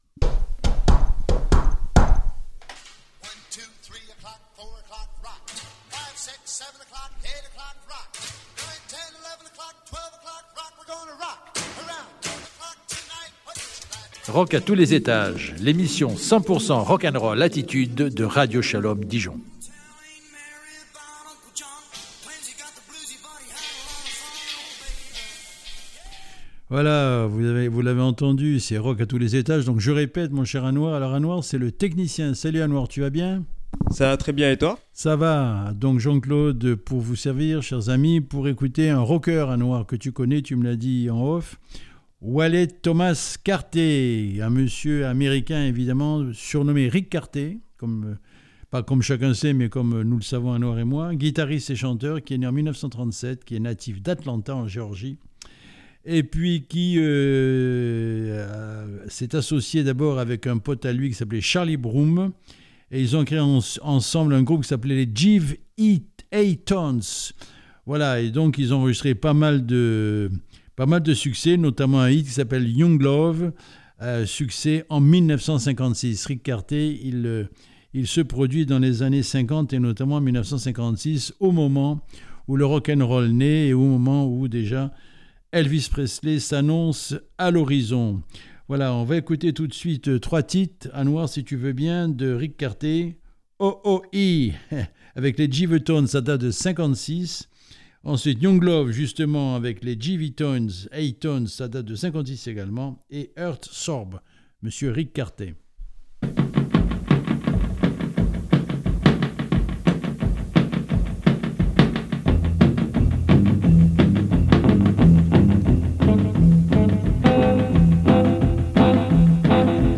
Ce podcast se consacre à Whaley Thomas Cartey mieux connu sous le nom de Ric Cartey. Américain rockabilly musicien et auteur-compositeur..